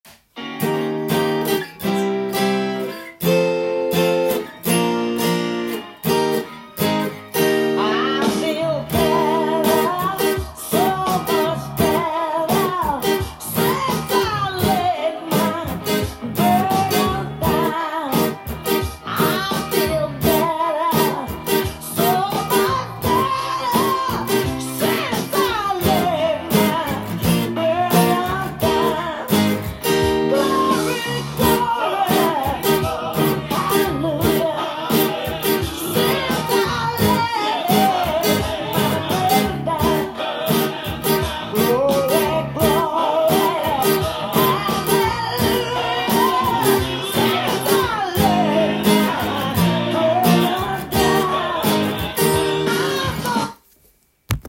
音源に合わせて譜面通り弾いてみました
keyがE♭になりますので
リズムは、ゴスペルの定番的なものになるので
１６分音符のシンコペーションが入ってきます。